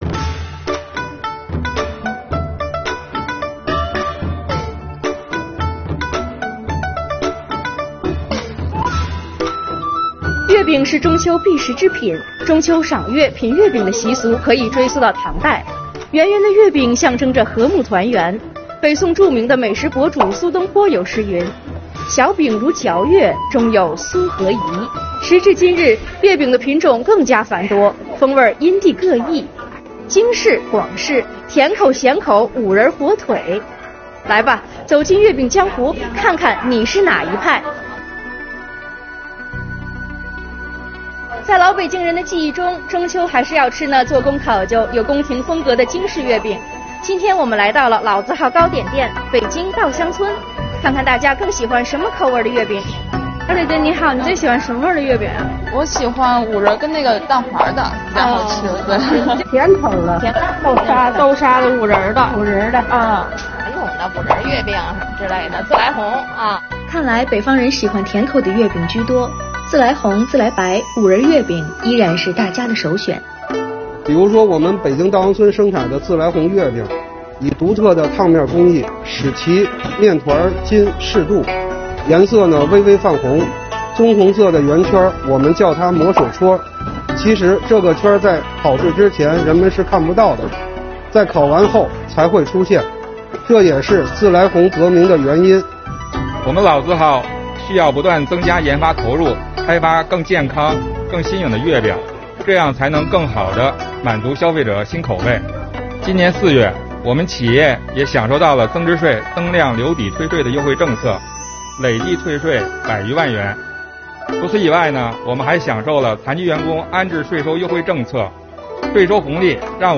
出镜主播